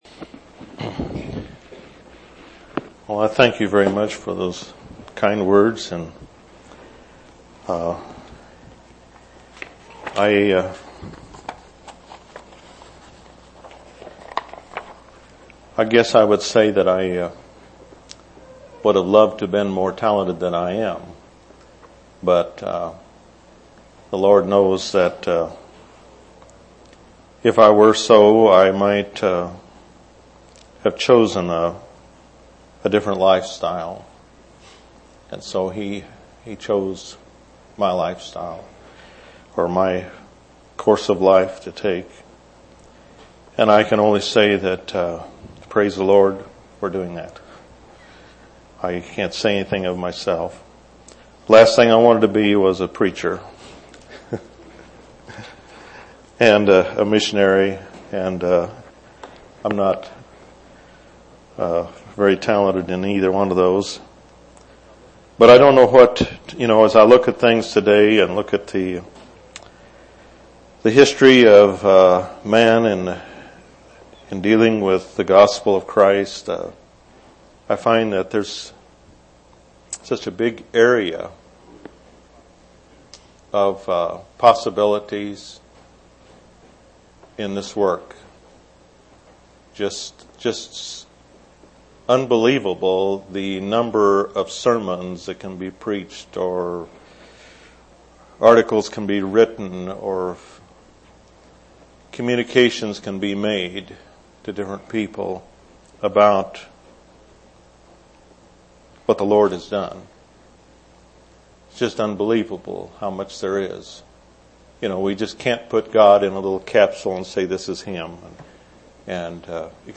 4/23/2000 Location: East Independence Local Event